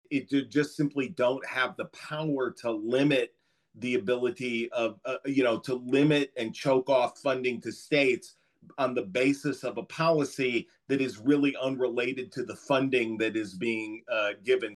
Illinois was the first state to end money bond statewide.  Monday afternoon, the Illinois Network for Pretrial Justice held a news conference supporting Illinois’ end to money bond.